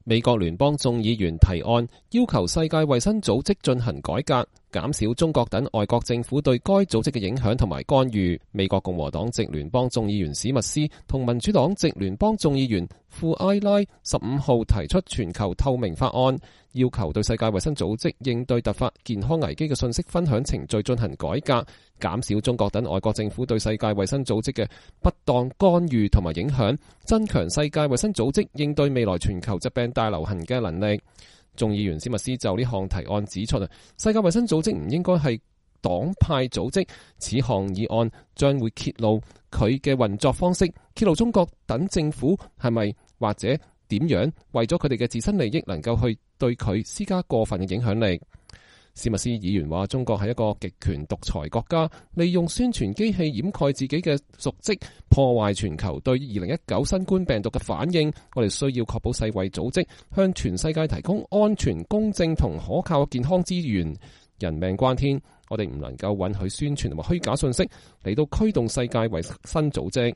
美國眾議員史密斯5月3日接受美國之音記者的採訪。